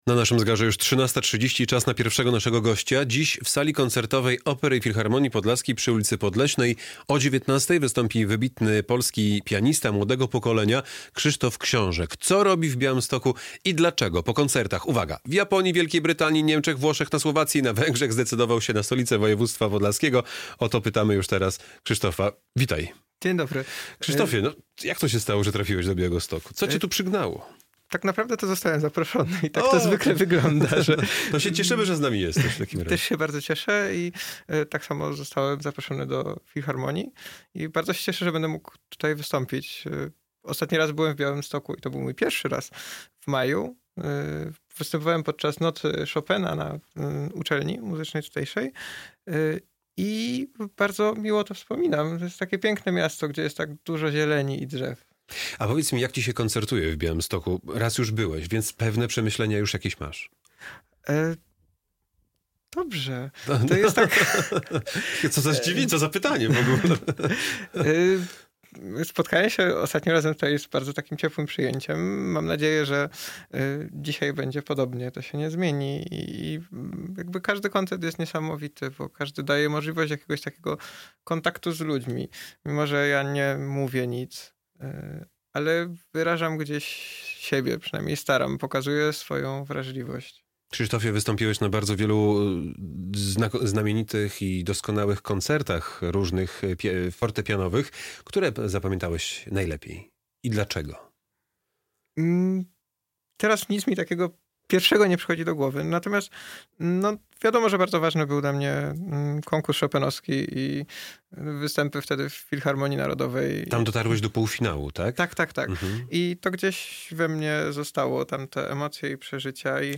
Studio Radia Bialystok